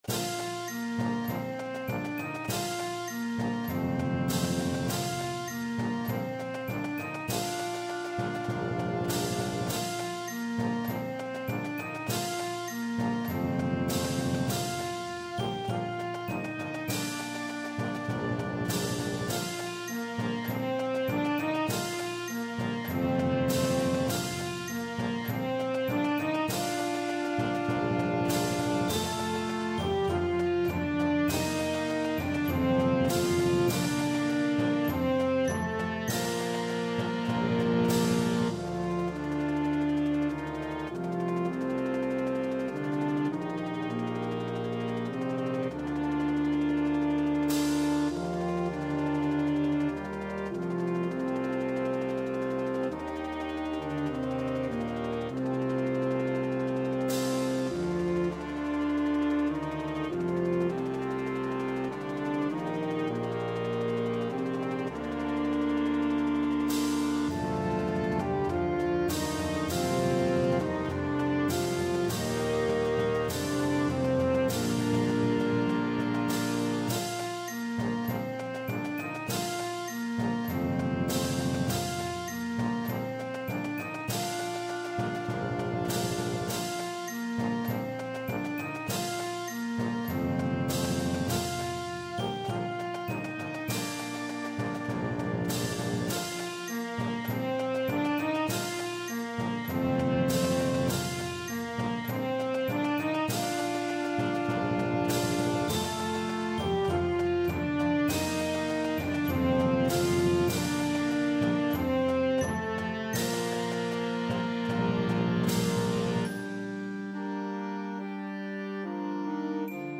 Typical fast , slow, fast piece.